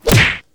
hit.wav